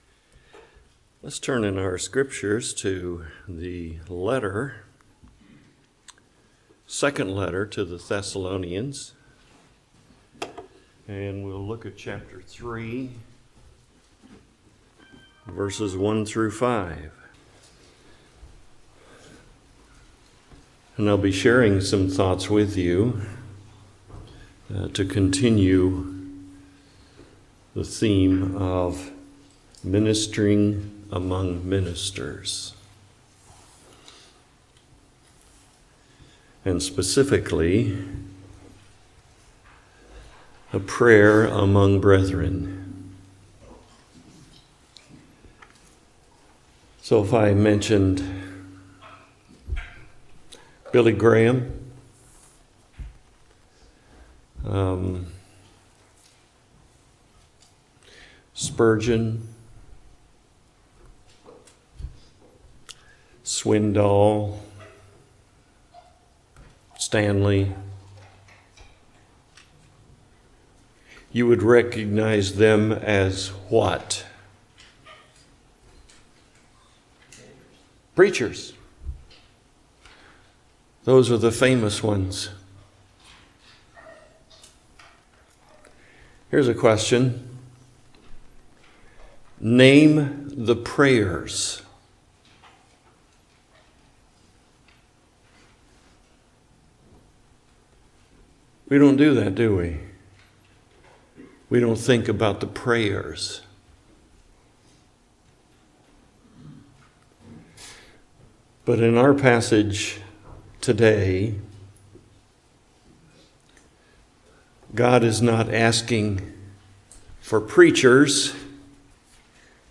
2 Thessalonians Passage: 2 Thessalonians 3:1-5 Service Type: Morning Worship Topics